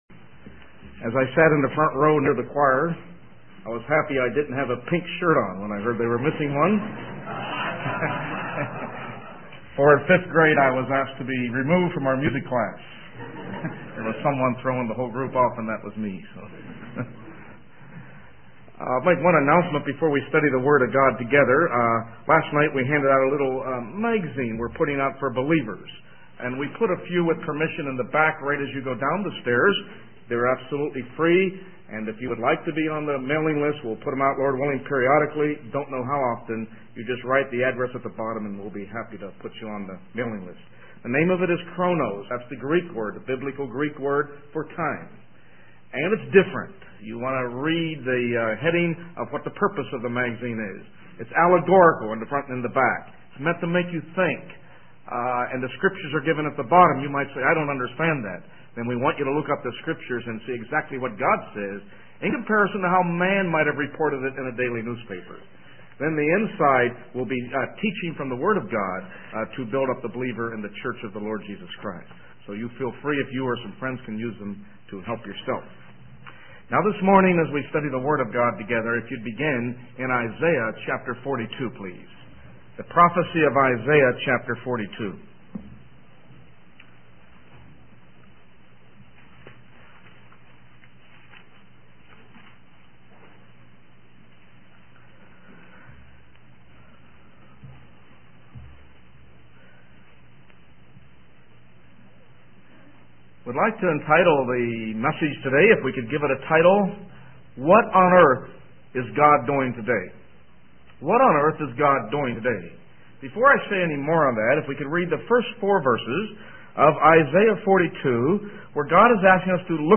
In this sermon, the speaker discusses the question of what God is doing on earth today. He begins by reading Isaiah 42:1-4, which speaks of God's servant, who is identified as Jesus Christ.